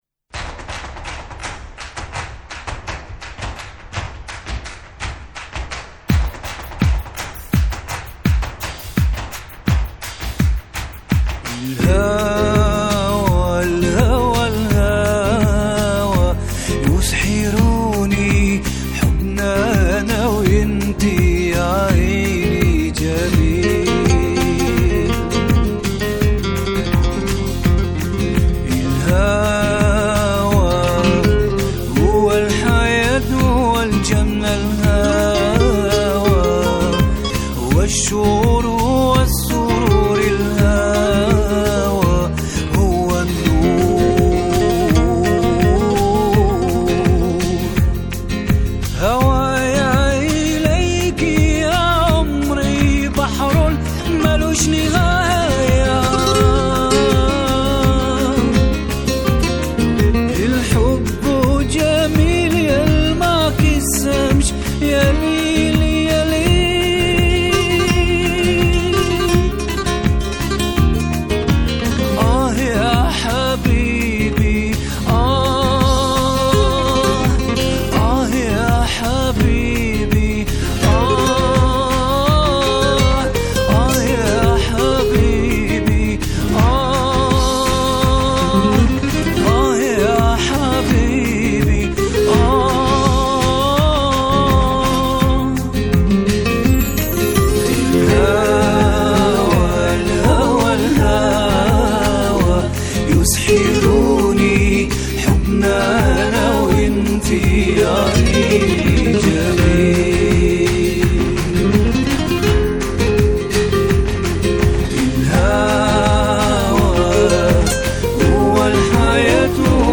一首很好听的弗拉门哥歌曲！！